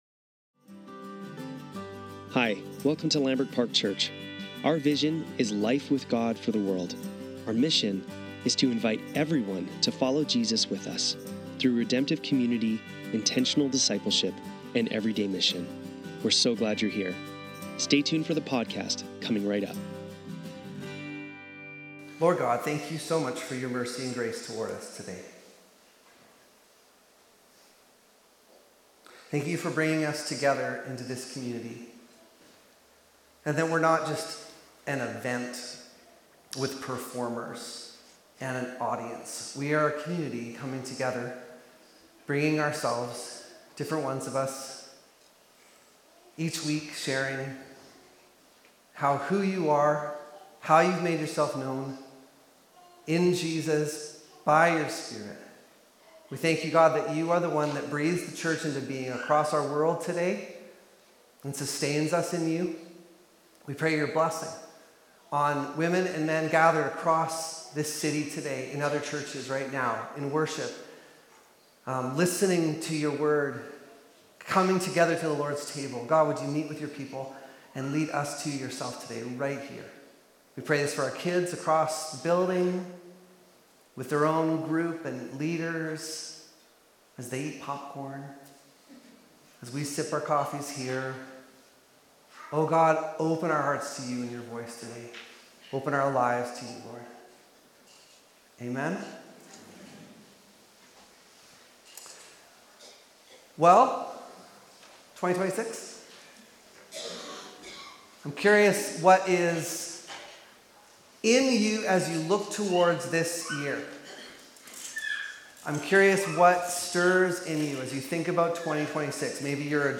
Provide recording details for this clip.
Sunday Service - January 4, 2026